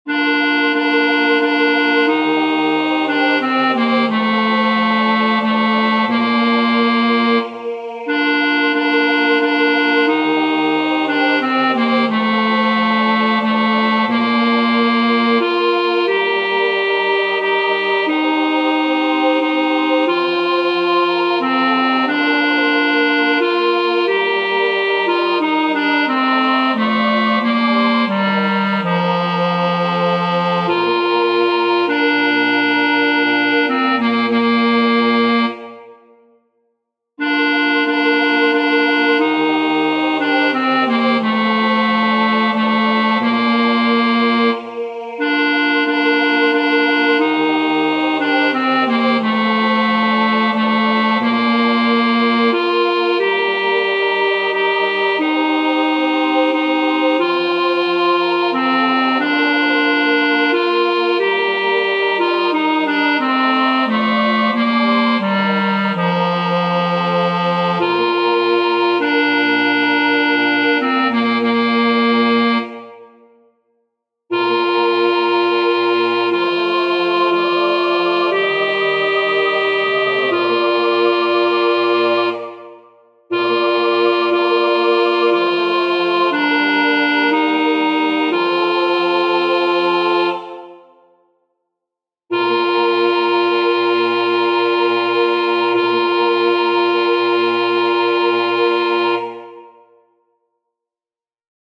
Para aprender la melodía os dejo los enlaces a los MIDIS según la primera versión que os expliqué más arriba, es decir, con el tenor a entrando a un tiempo de espera.
La melodía es muy sencilla y fácil de aprender.
ave_vera_virginitas-alto.mp3